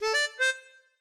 melodica_ad1c1.ogg